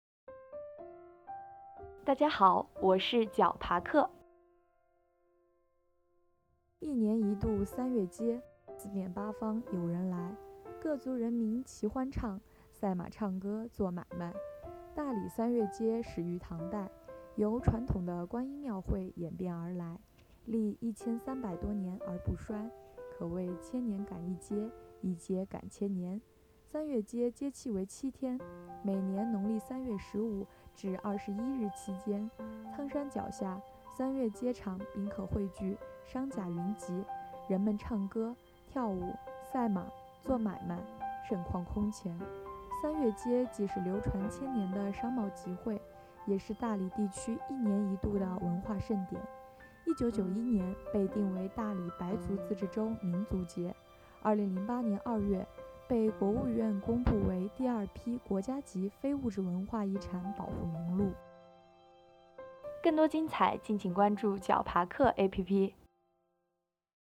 解说词